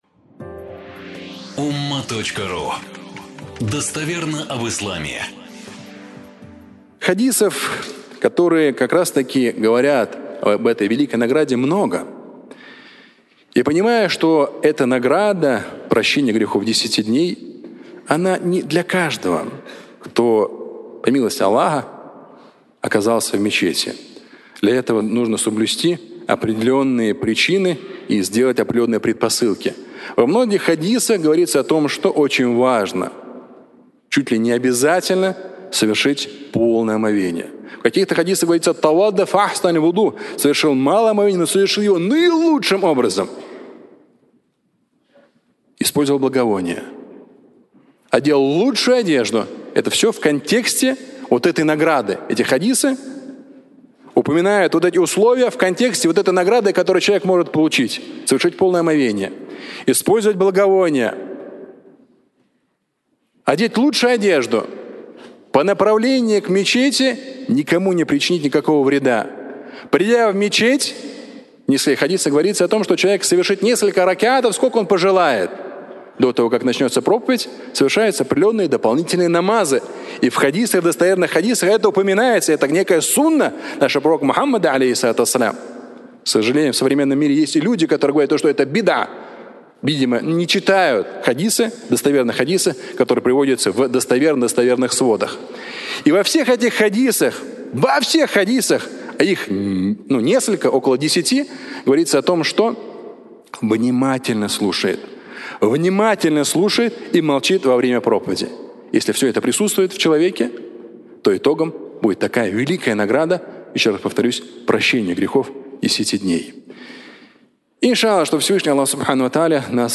Пятничная проповедь